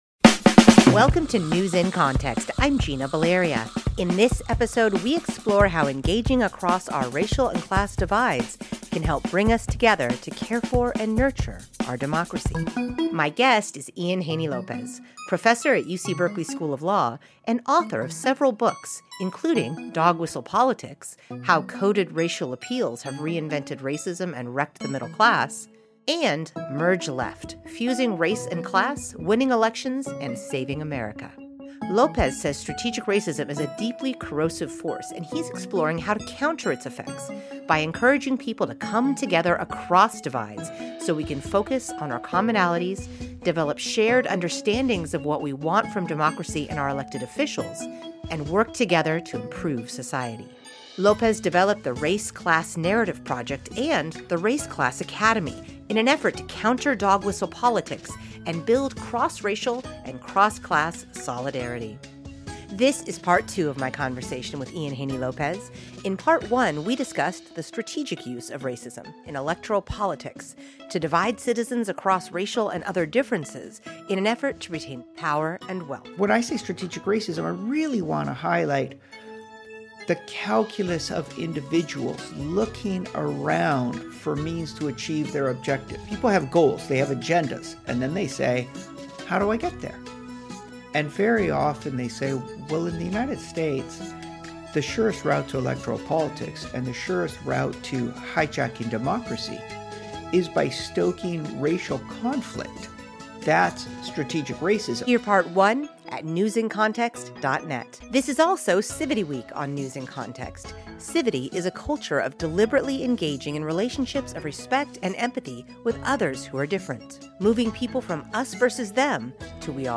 This is Part Two of our interview.
My guest is Ian Haney López, professor at UC Berkeley School of Law, and author of several books, including Dog Whistle Politics: How Coded Racial Appeals Have Reinvented Racism & Wrecked the Middle Class, and Merge Left: Fusing Race & Class, Winning Elections, and Saving America.